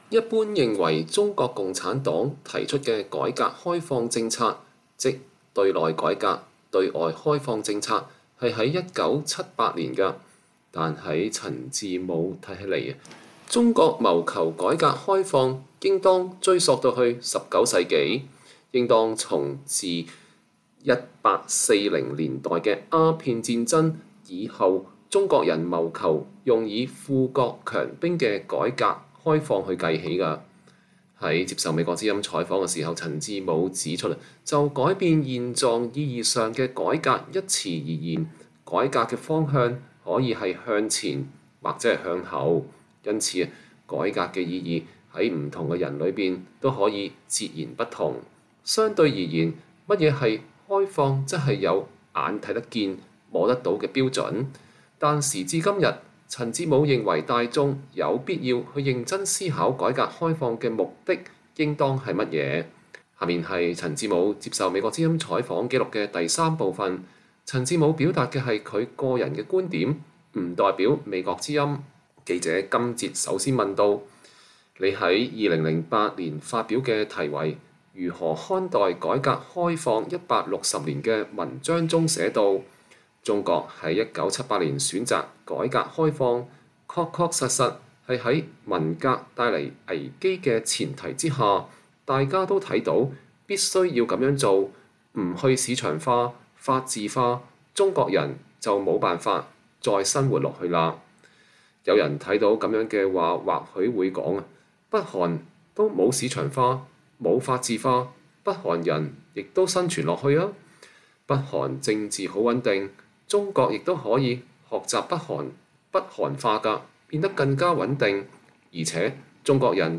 專訪陳志武（3）：中國改革開放170多年來的嬗變